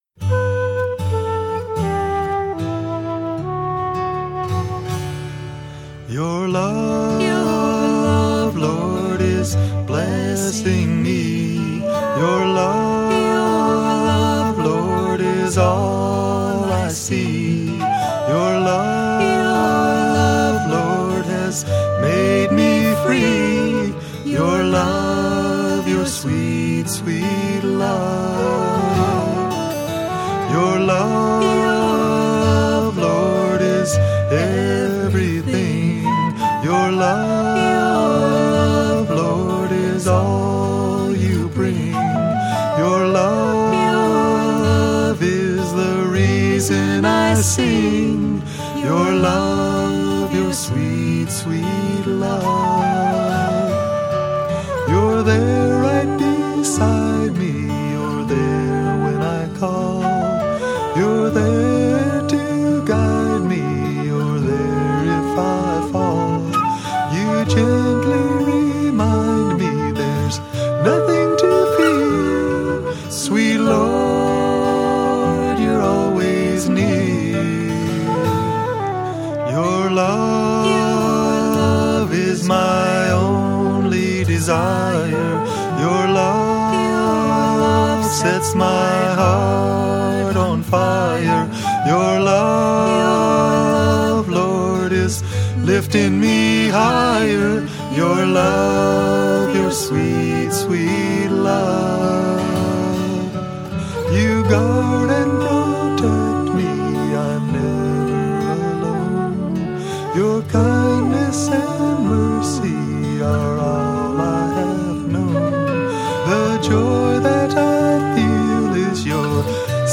1. Devotional Songs
Major (Shankarabharanam / Bilawal)
8 Beat / Keherwa / Adi
1 Pancham / C
5 Pancham / G
Lowest Note: S / C
Highest Note: G2 / E (higher octave)